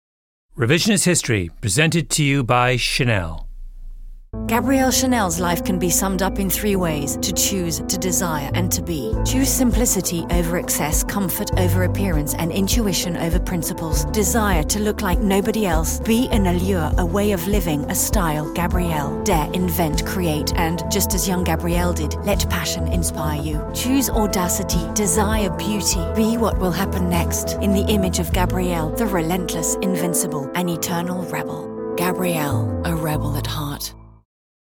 This is the ad / sponsorship thing that Chanel run at the beginning of the Revisionist History podcast.
It's like someone's just reading out a mood board.
There's a sort of generic classy piano soundtrack with a gabbling, garbled voice-over, presumably so they can fit in all the extra meaningless words.